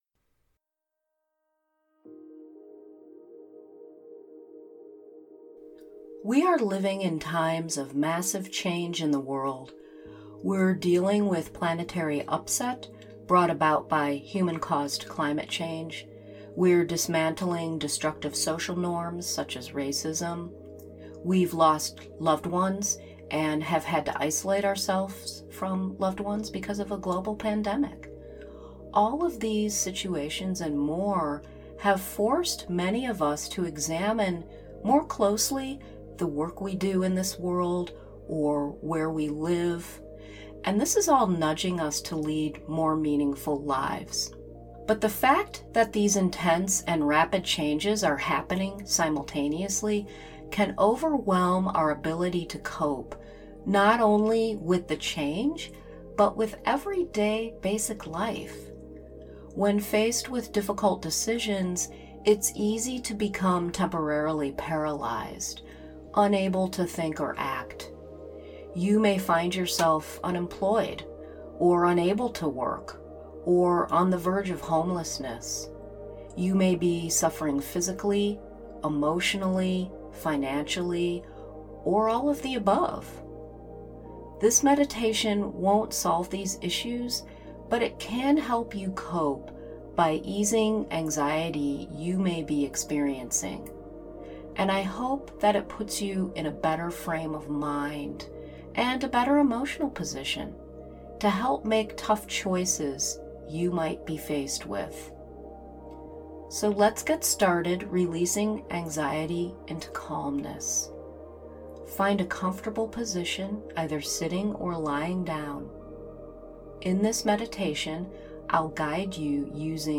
Yet, the mind is a powerful tool through which, barring any underlying conditions, you can control your emotional experiences. Use this meditation to release anxiety and invite a sense of calmness into your being.